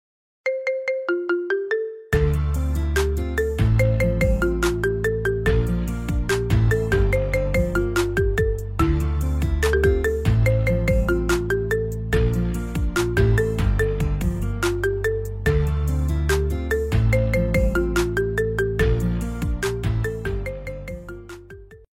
Kategorien Marimba Remix